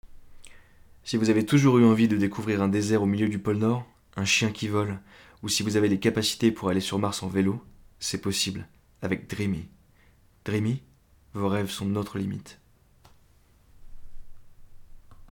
pub